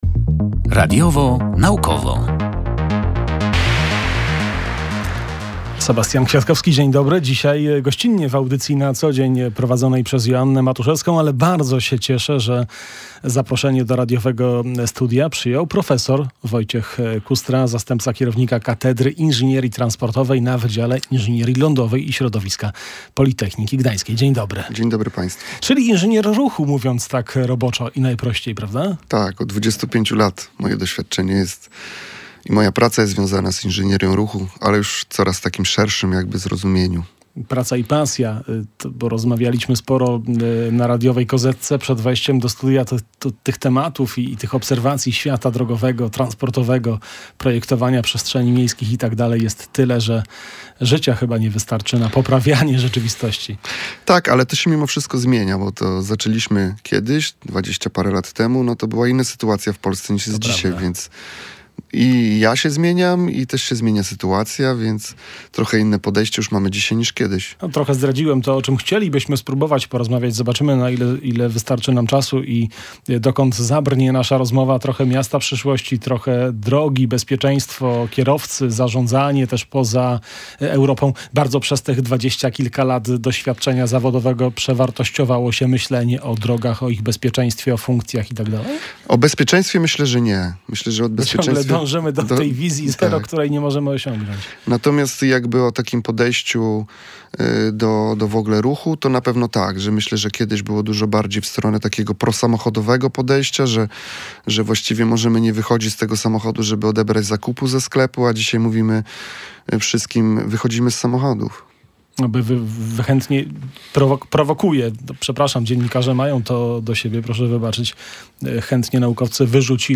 Bezpieczeństwo na drogach okiem eksperta w audycji „Radiowo-Naukowo”